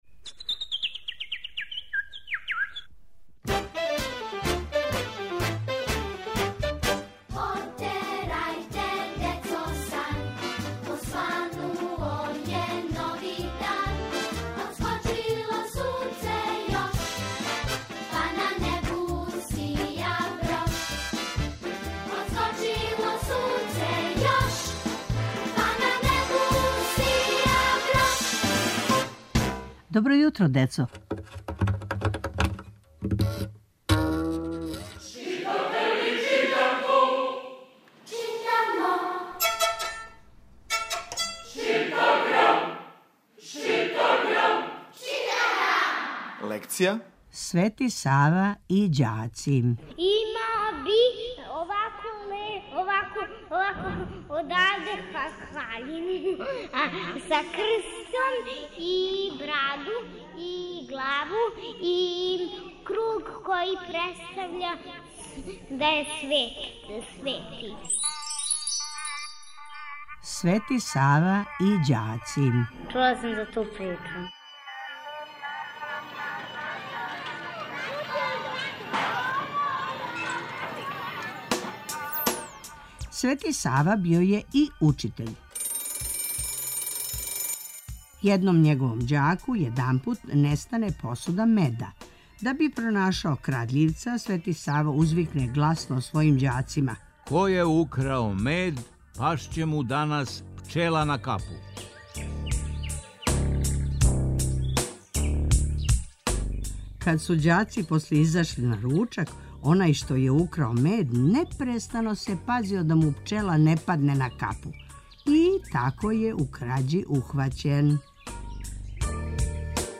Сваког понедељка у емисији Добро јутро, децо - ЧИТАГРАМ: Читанка за слушање. Ове недеље - први разред, лекција: Свети Сава.